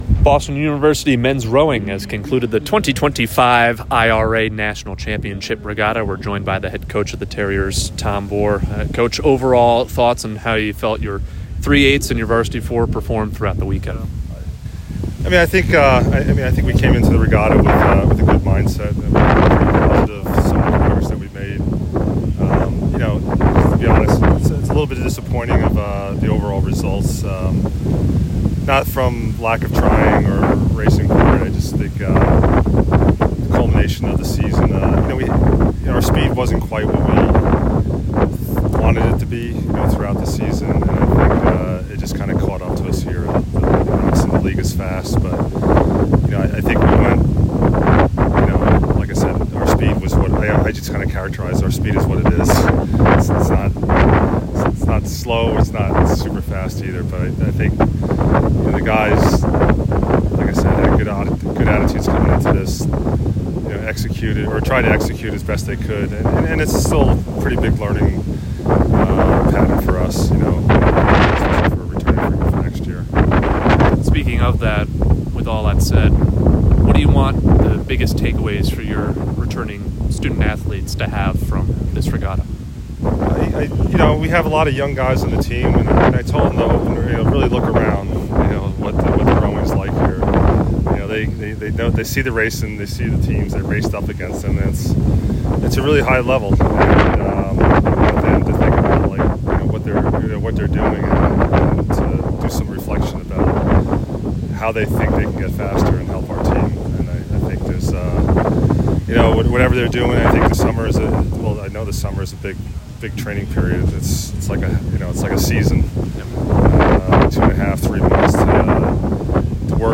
IRA Championship Post Regatta Interview